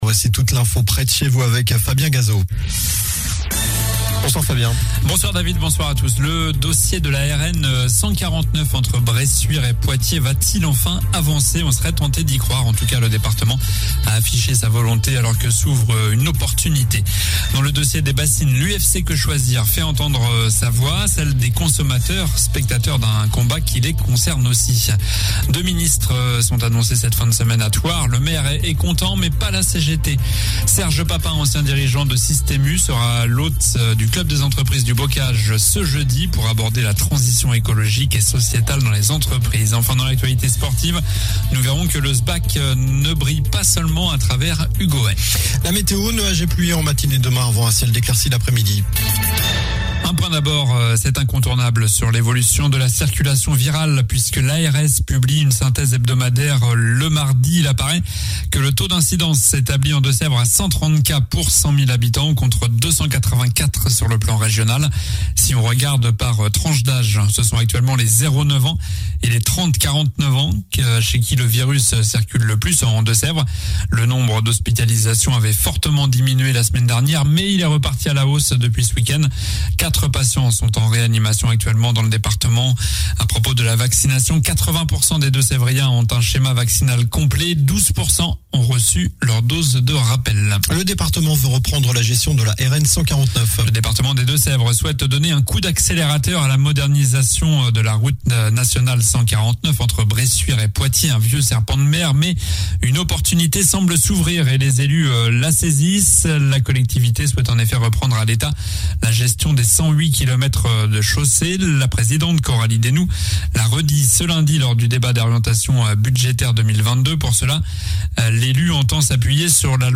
Journal du mercredi 30 juin (soir)